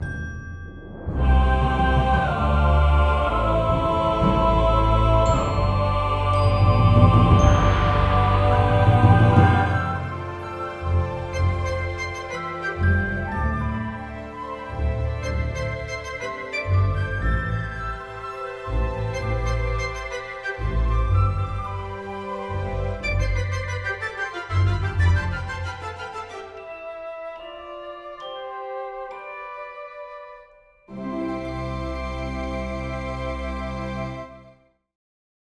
Optional Background Music for Section 7